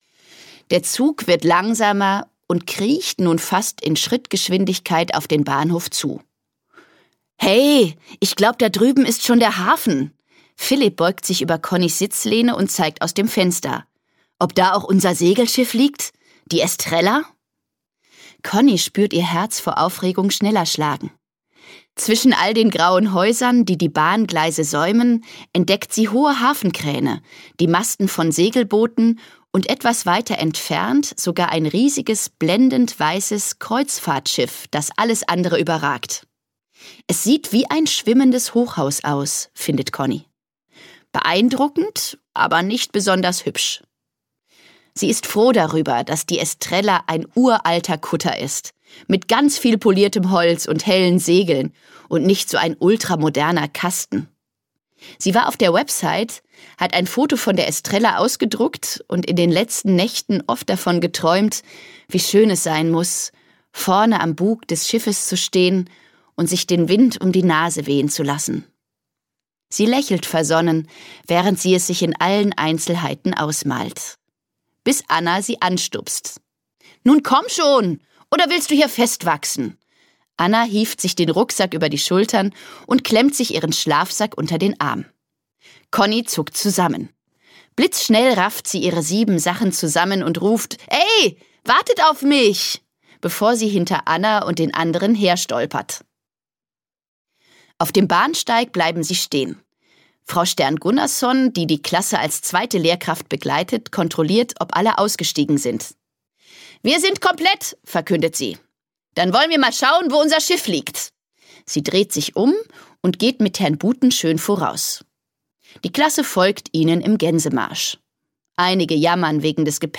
Conni & Co 17: Conni, Billi und das schwimmende Klassenzimmer - Dagmar Hoßfeld - Hörbuch